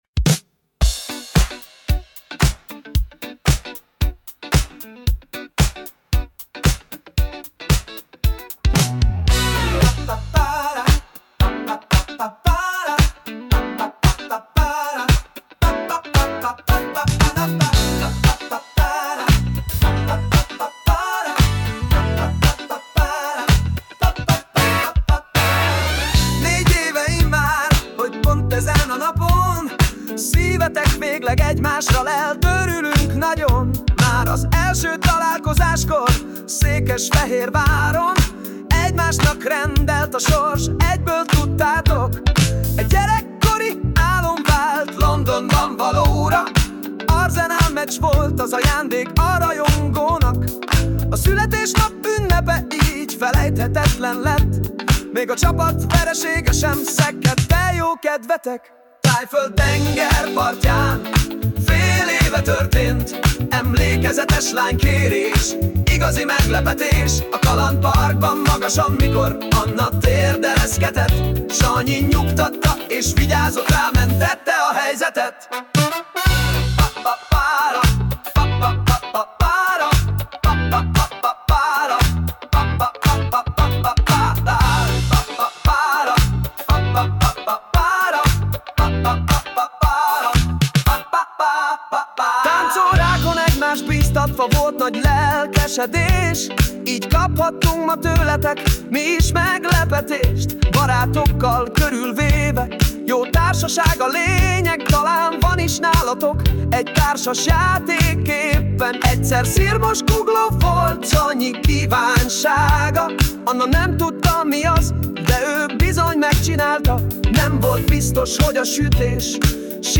Funky - Esküvőre